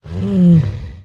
Minecraft Version Minecraft Version snapshot Latest Release | Latest Snapshot snapshot / assets / minecraft / sounds / mob / polarbear / idle3.ogg Compare With Compare With Latest Release | Latest Snapshot